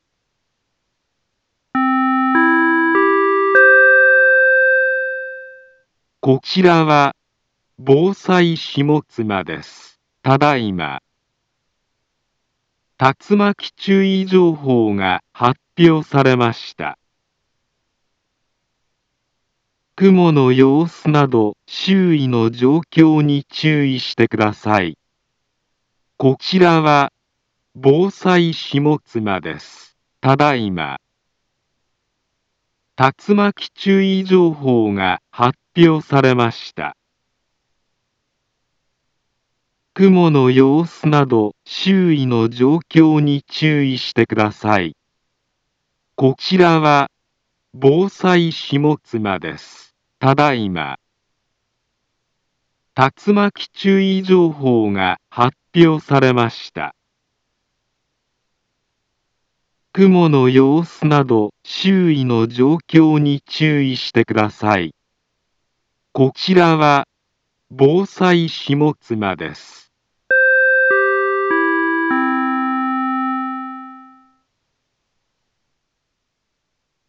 Back Home Ｊアラート情報 音声放送 再生 災害情報 カテゴリ：J-ALERT 登録日時：2021-12-01 07:15:14 インフォメーション：茨城県北部、南部は、竜巻などの激しい突風が発生しやすい気象状況になっています。